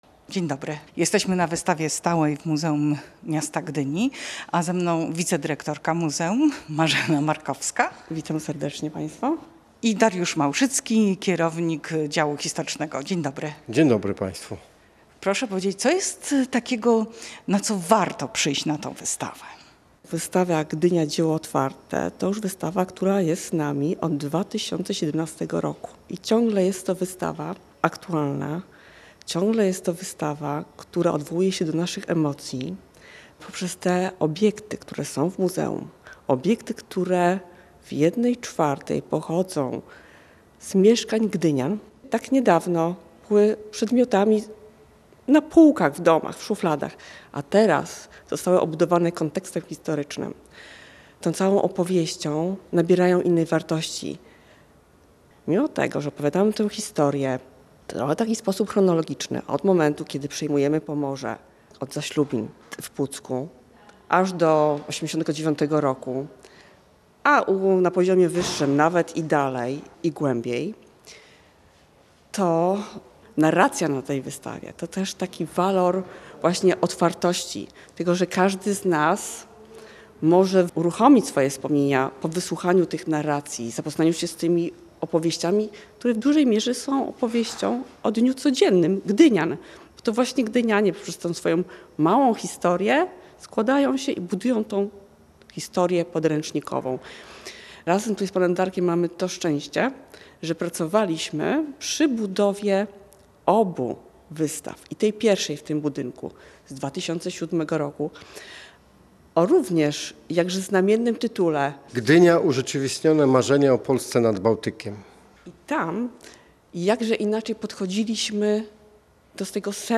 zwiedziła z mikrofonem